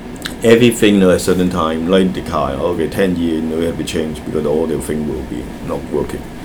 S1 = Taiwanese female S2 = Hong Kong male Context: S2 is talking about how a house in the United States needs constant maintenance. S2 : ... everything you know at certain time like the tile okay ten year you know you have to change because all the thing will be (.) not working Intended Words : tile Heard as : tire Discussion : The [ɫ] undergoes vocalisation (so it is pronounced as a vowel rather than a lateral).